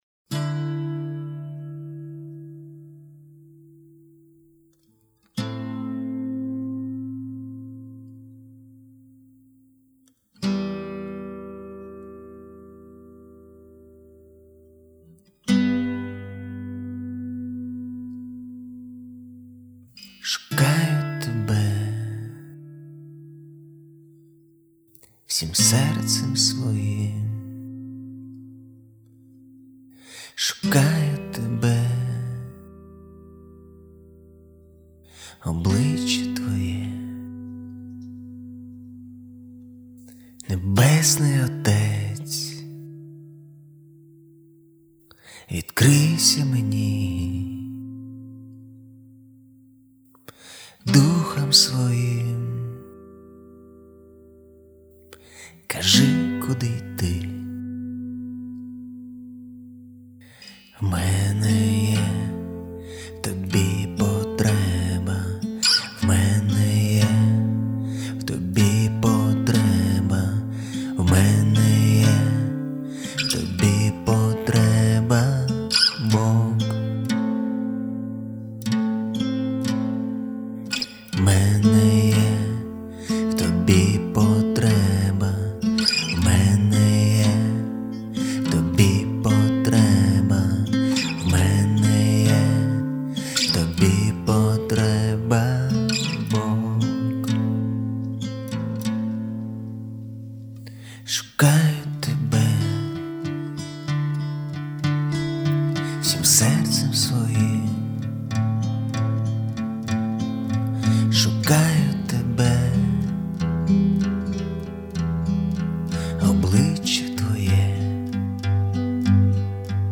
песня
65 просмотров 105 прослушиваний 2 скачивания BPM: 95